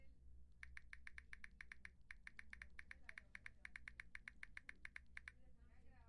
描述：一只手拍打玻璃
Tag: 湿 耳光 玻璃